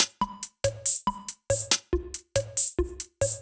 Index of /musicradar/french-house-chillout-samples/140bpm/Beats
FHC_BeatD_140-01_Tops.wav